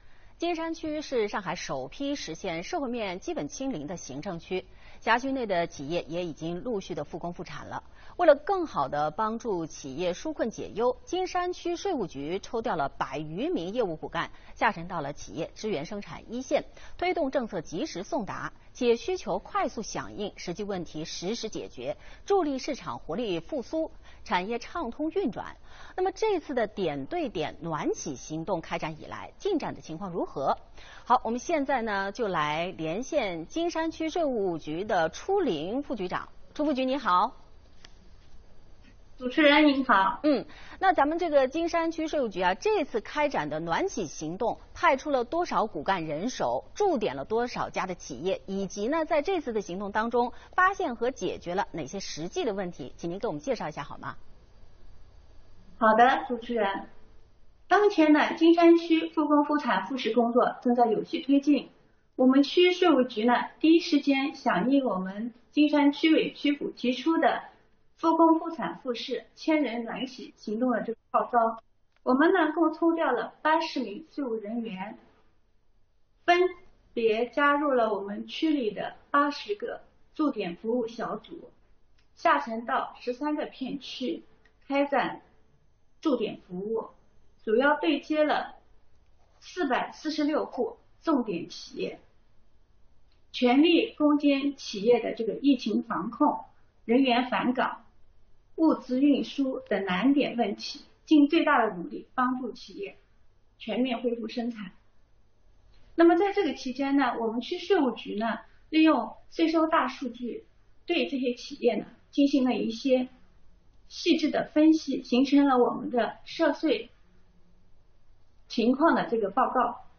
5月20日，东方卫视《看东方》栏目直播连线区局，就近期助力企业复工复产复市“千人暖企”行动情况接受记者采访。